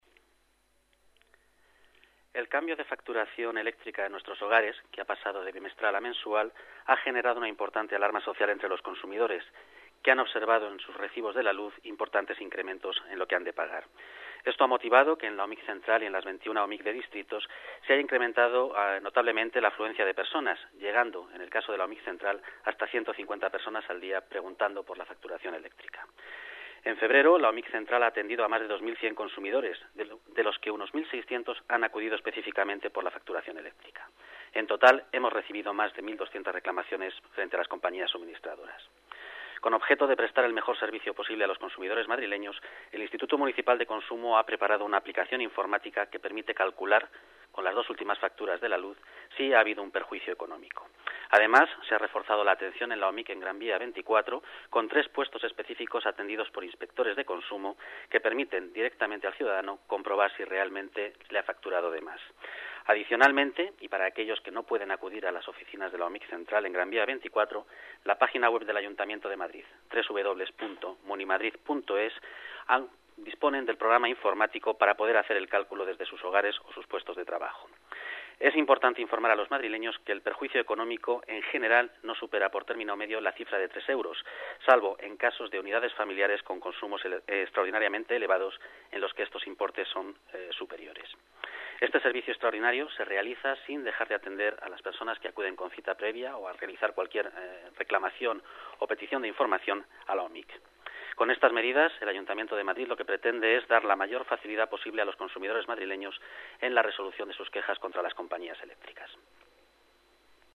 Nueva ventana:Declaraciones de Ángel Sánchez, director general de Consumo